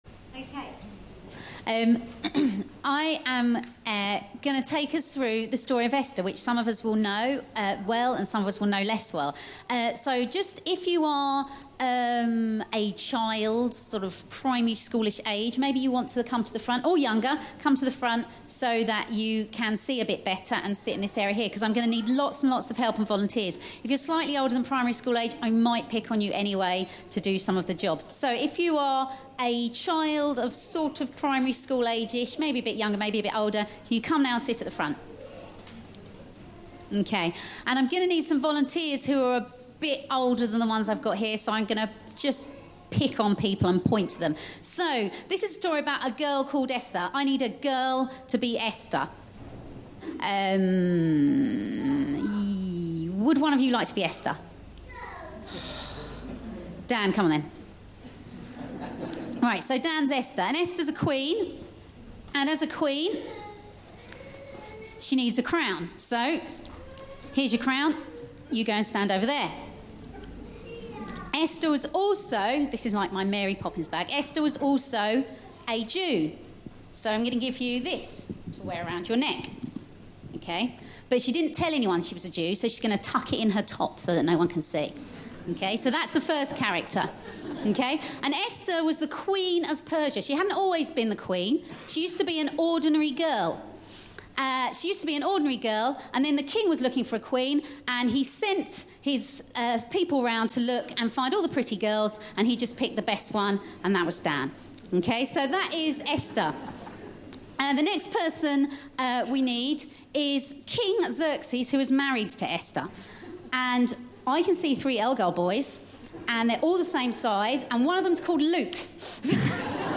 A sermon preached on 25th August, 2013.